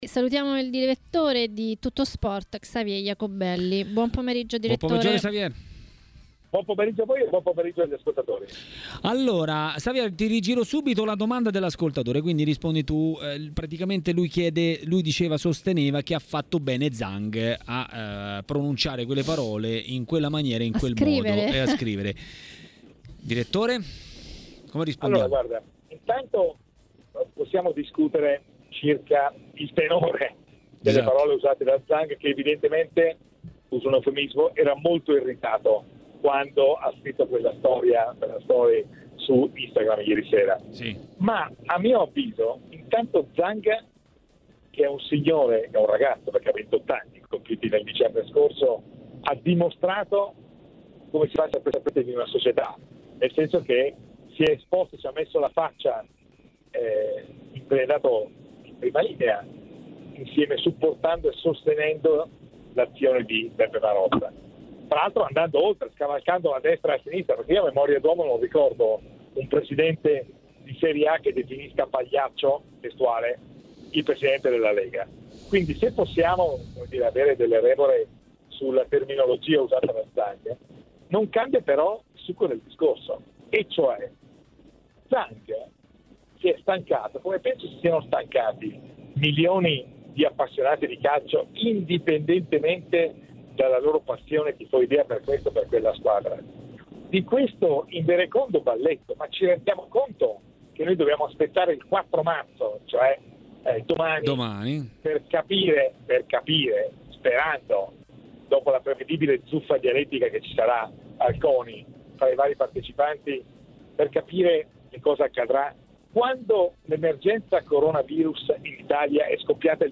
è intervenuto a TMW Radio, durante Maracanà, per parlare di Milan e non solo.